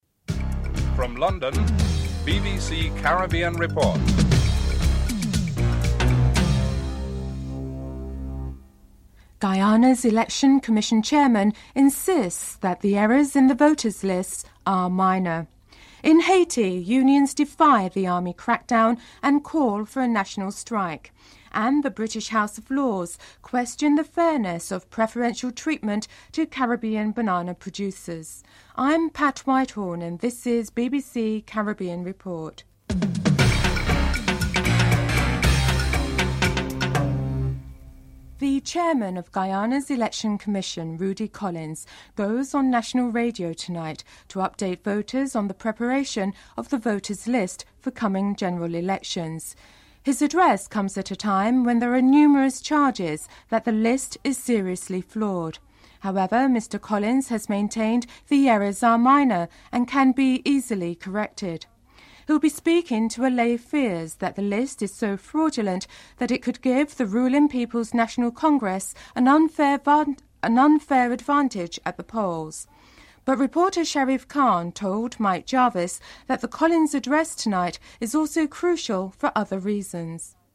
1. Headlines (00:00-00:37)